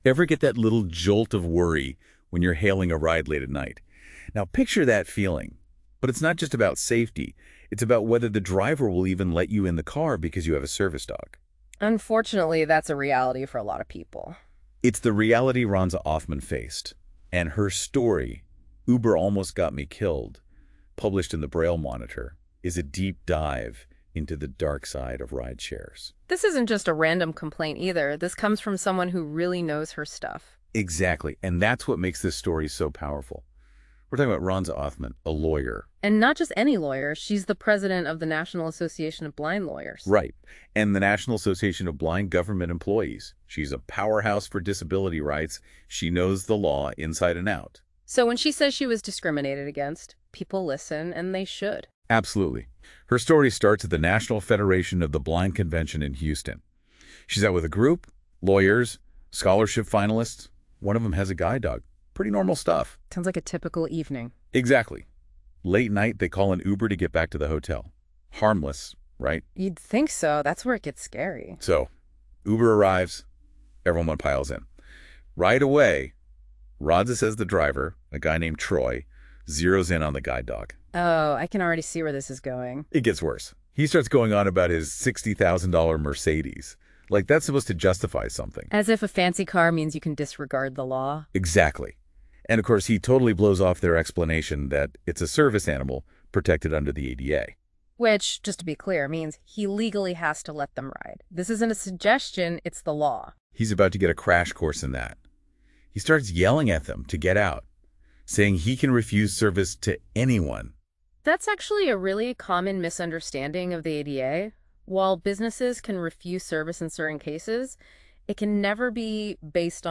It basically creates a podcast with two AI generated voices based on the source documents you upload....